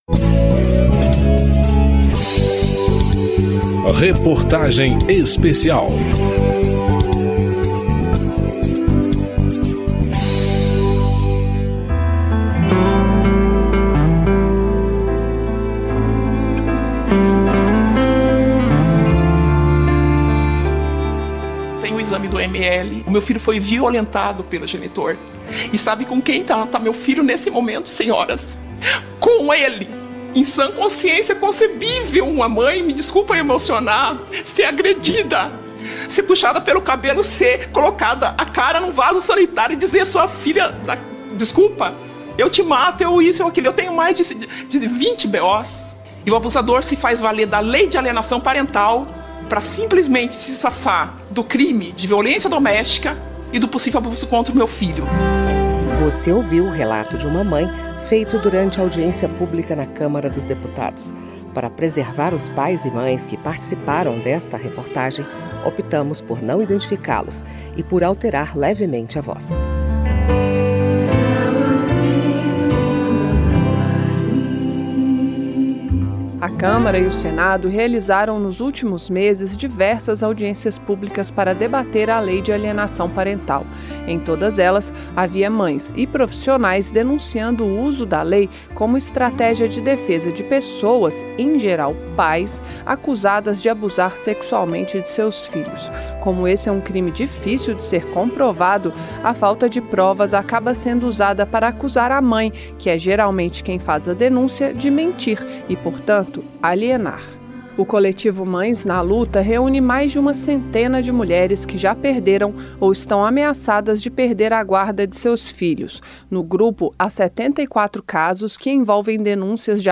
Reportagem Especial
Você ouviu o relato de uma mãe feito durante audiência pública na Câmara dos Deputados. Para preservar os pais e mães que participaram desta reportagem, optamos por não identificá-los e por alterar levemente a voz.